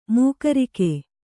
♪ mūkarike